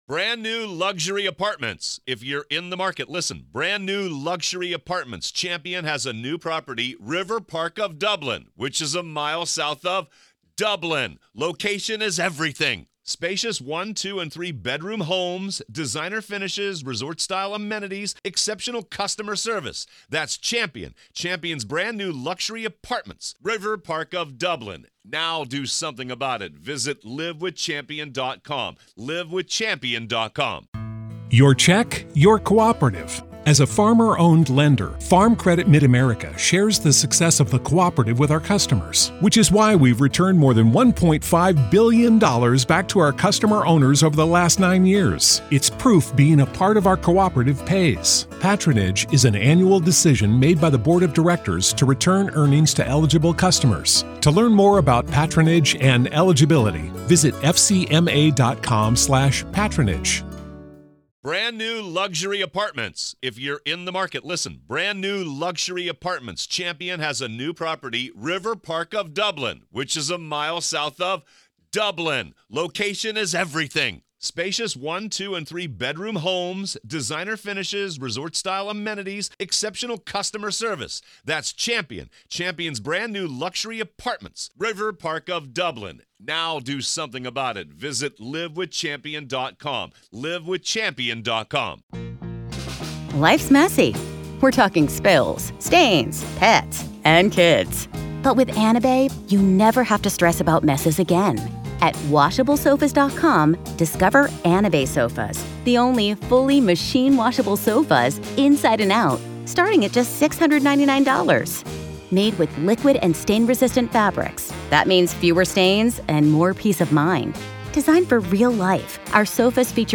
Dr. Katherine Ramsland Interview Behind The Mind Of BTK Part 2